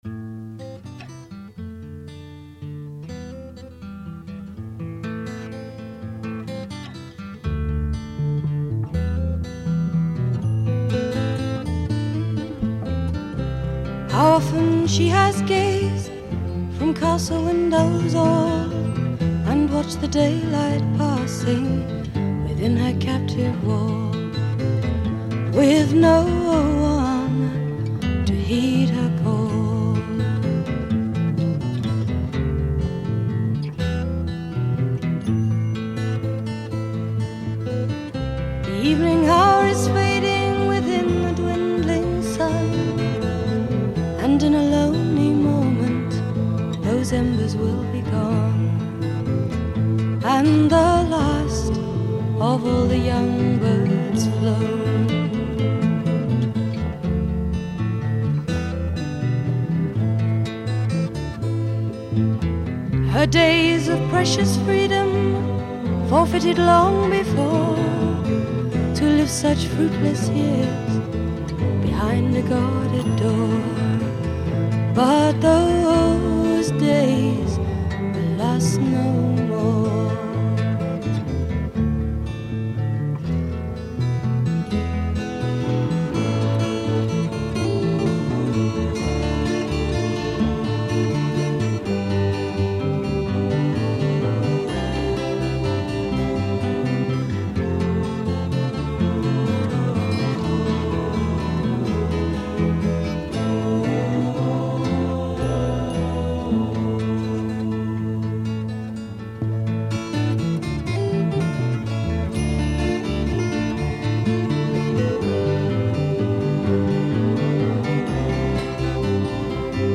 providing the fluid, folky and atmospheric acoustic guitar.